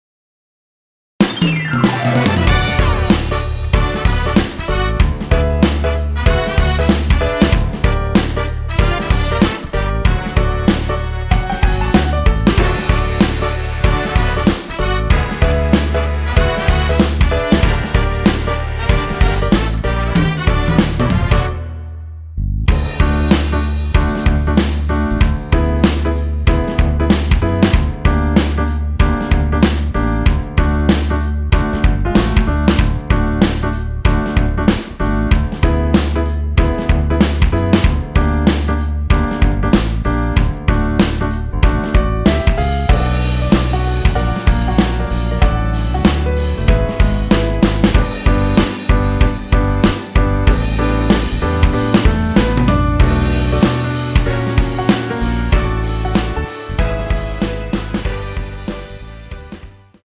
앞부분30초, 뒷부분30초씩 편집해서 올려 드리고 있습니다.
중간에 음이 끈어지고 다시 나오는 이유는
위처럼 미리듣기를 만들어서 그렇습니다.